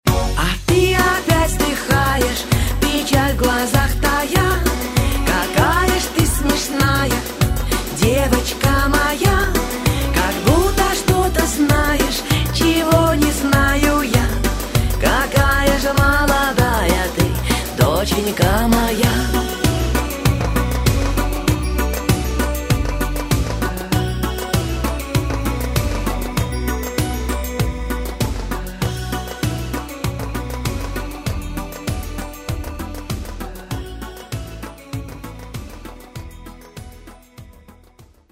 Женский голос
Ретро